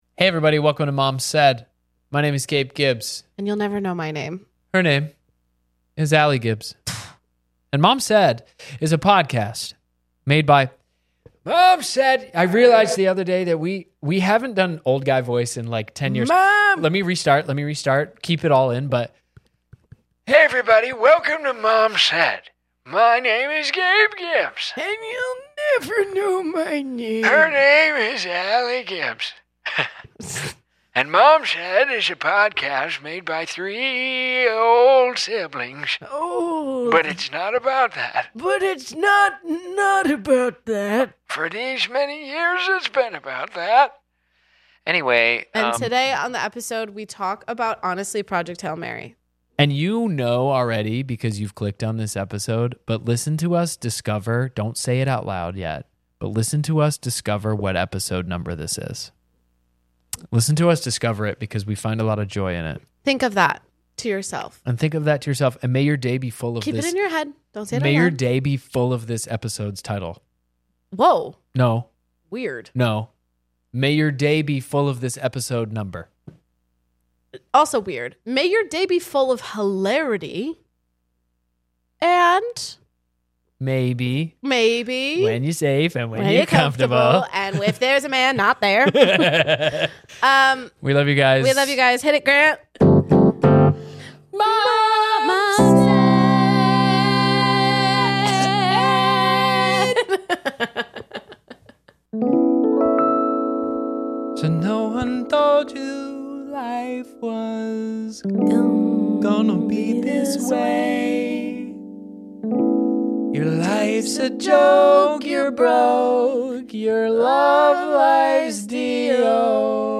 This week the siblings chat about project Hail Mary, collage art, and the deliciousness of debiled eggs.